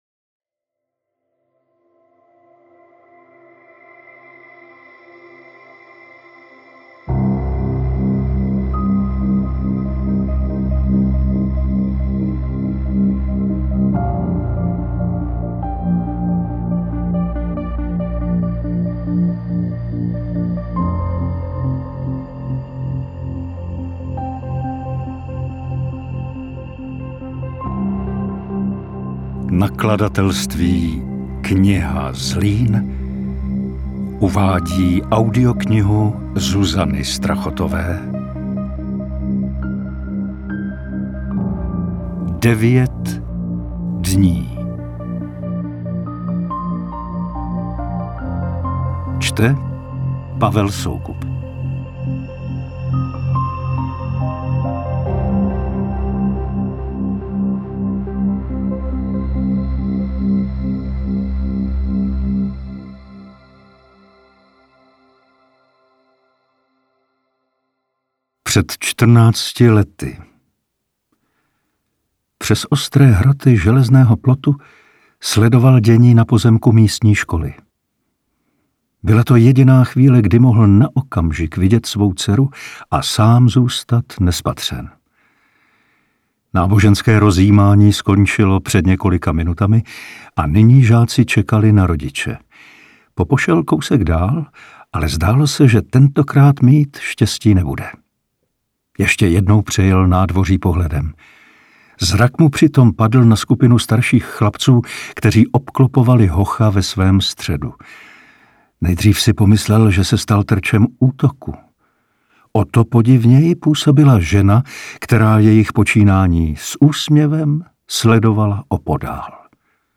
Interpret:  Pavel Soukup
AudioKniha ke stažení, 37 x mp3, délka 13 hod. 55 min., velikost 1142,5 MB, česky